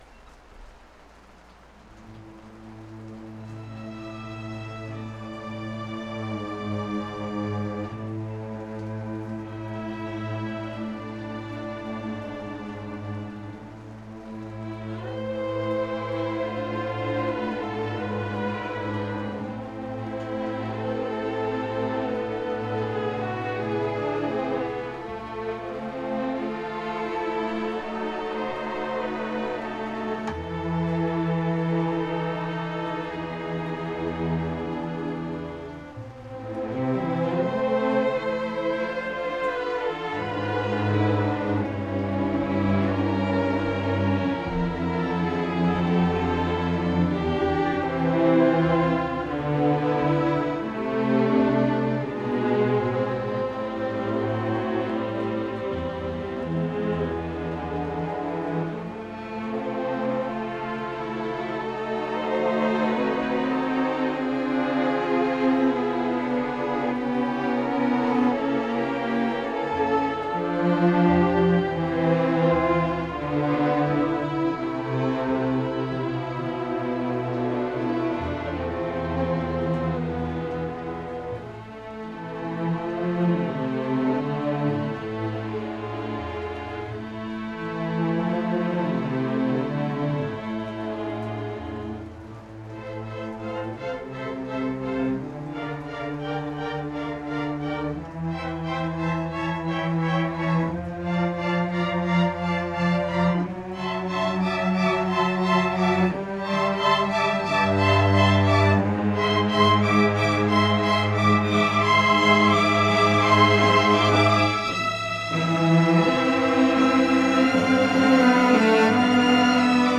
2:00 PM on August 3, 2014, "On the Deck"
Orchestra